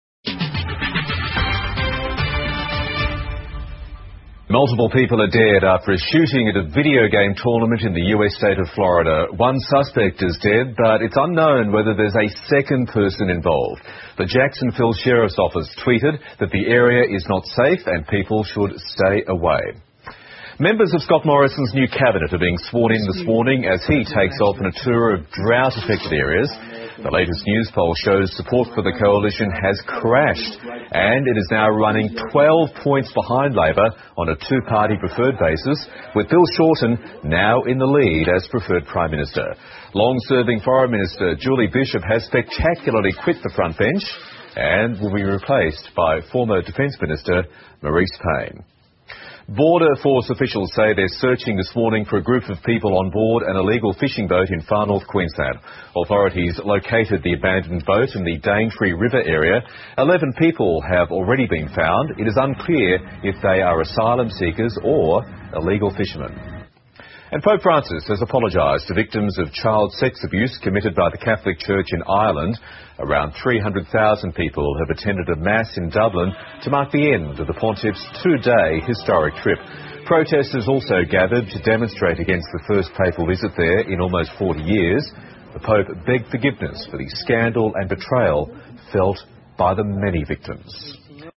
澳洲新闻 (ABC新闻快递) 美佛罗里达州发生枪击案 澳大利亚新总理莫里森走马上任 听力文件下载—在线英语听力室